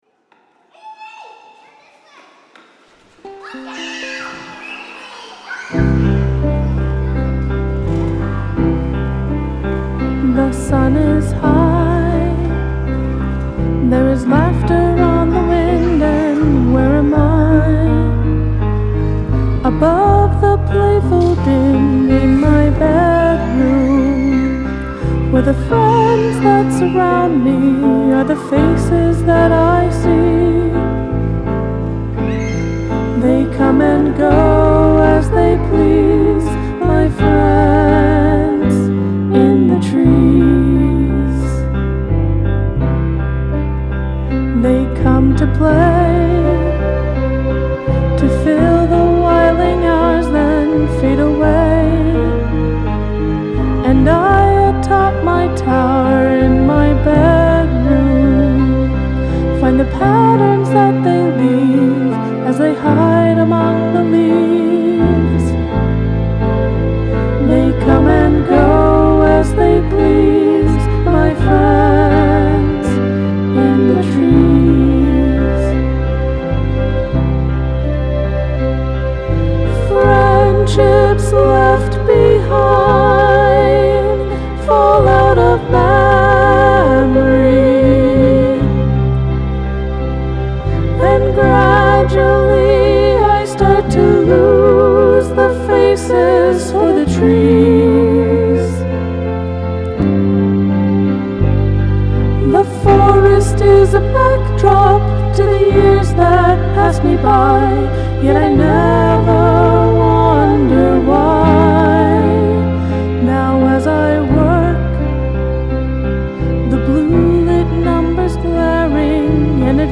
Use of field recording
Mainly cos the faux-almost-operatic vocal style choice.